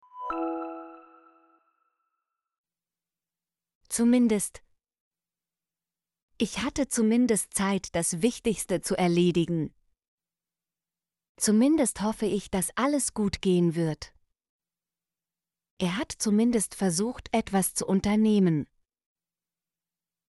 zumindest - Example Sentences & Pronunciation, German Frequency List